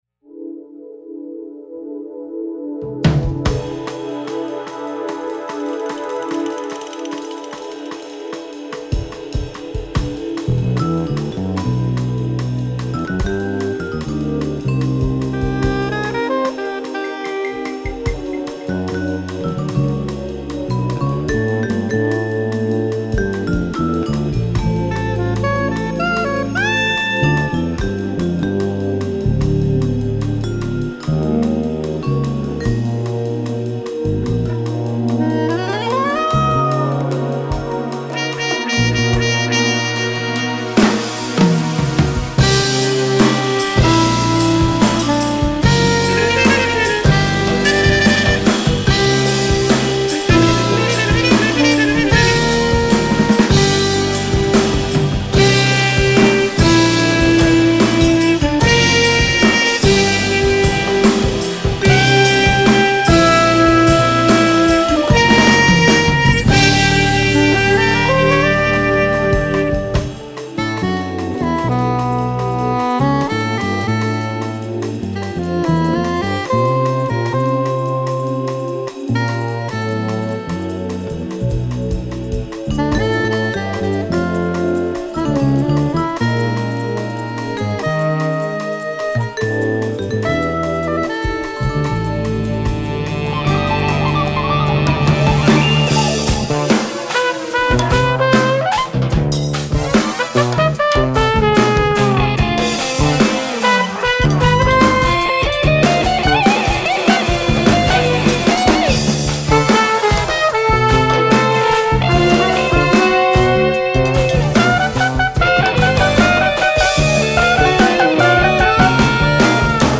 Percussion, Vibraphone, Sound Effects
Electric Guitar
Soprano Saxophone